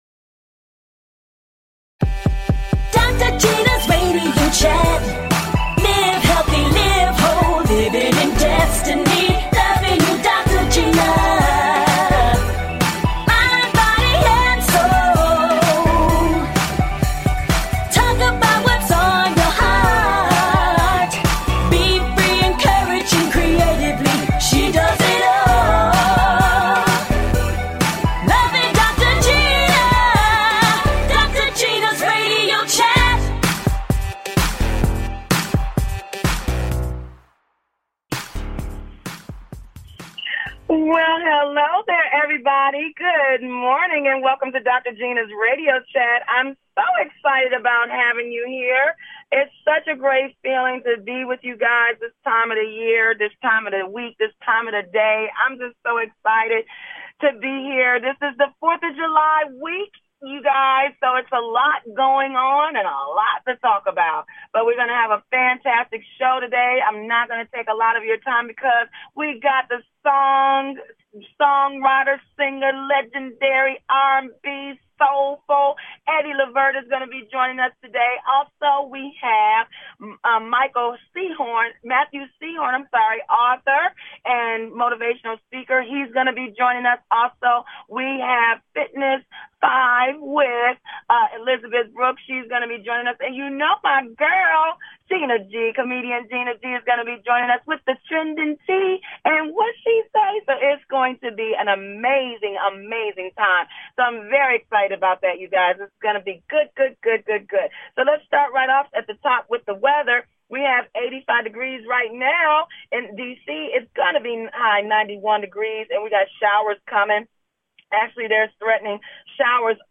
Talk Show
And full of laughter!